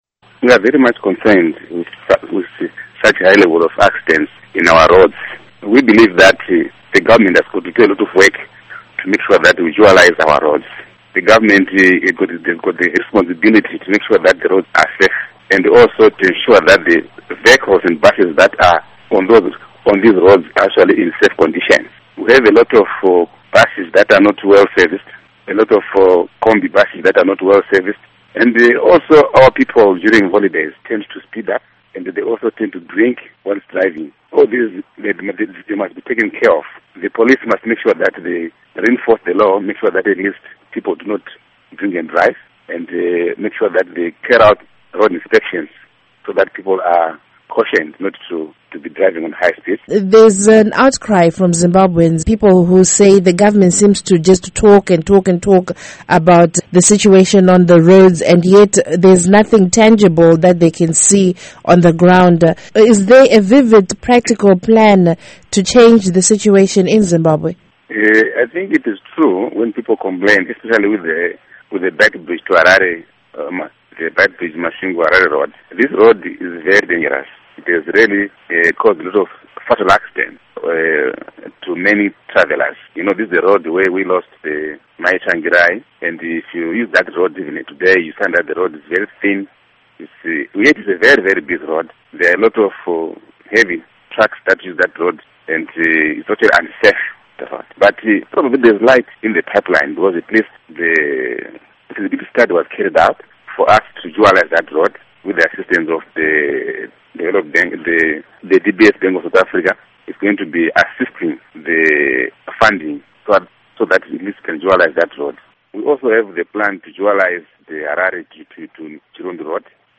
Interview wih Morgan Komichi